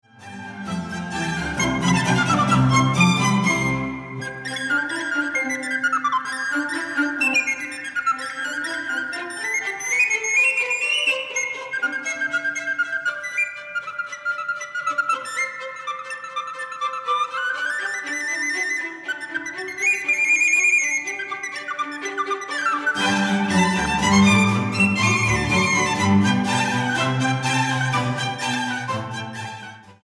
para flauta sopranino, cuerdas y continuo